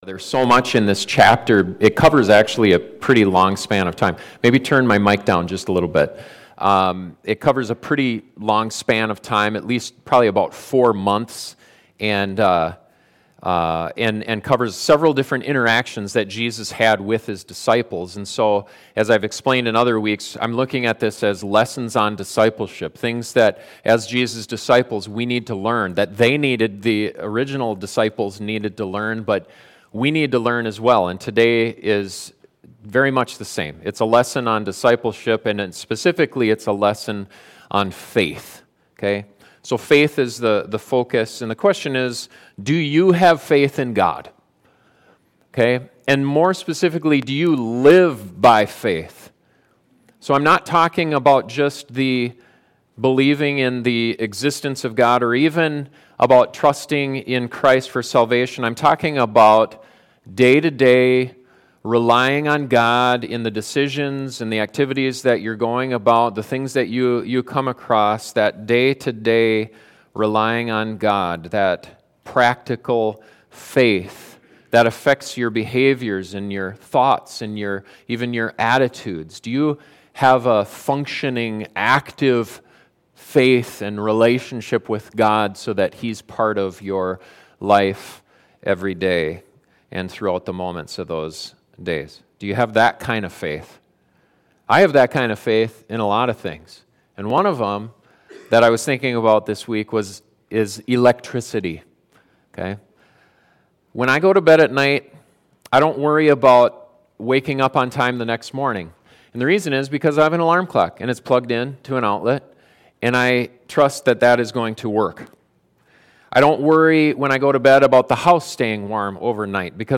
Do you rely on God throughout your day, or do you turn to him as a last resort? The biblical story covered in this sermon will help you assess you life and your faith and encourage you how to grow.